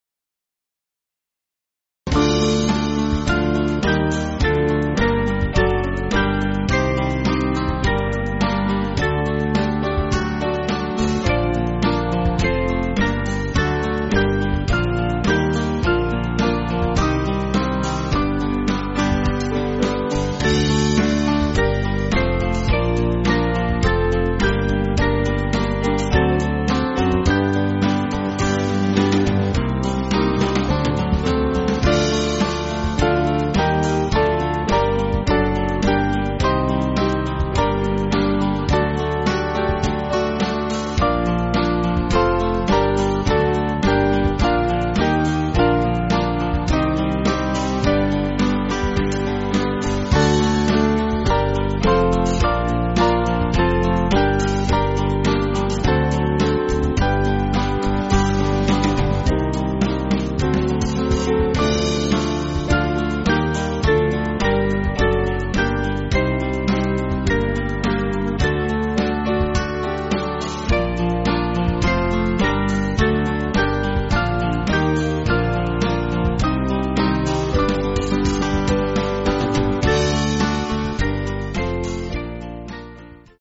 Small Band
(CM)   4/Gm